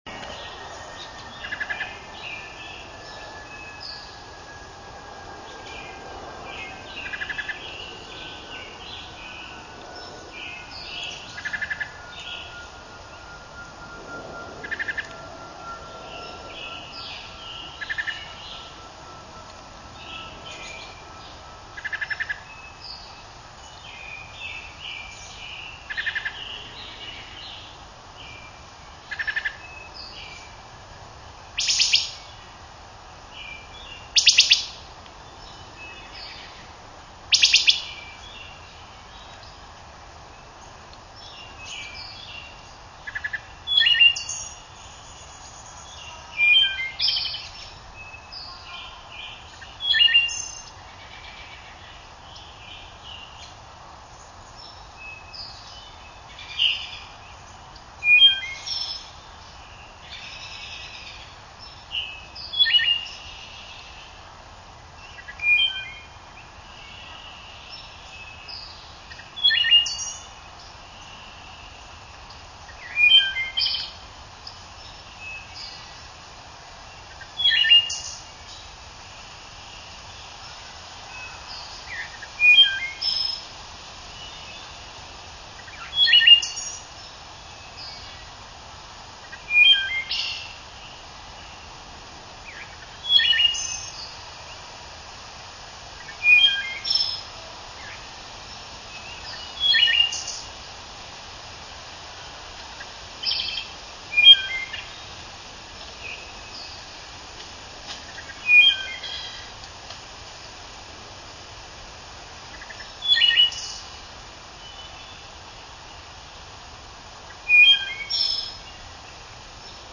Wood Thrush
Three Wood Thrush sounds are featured on this clip.  The soft sounding, introductory, tremulous "ka-ka-ka-ka" are from the female.
Other birds in the background are the Red-eyed Vireo at the beginning and the Blue Jay "styer" toward the end.
thrush_wood_805.wav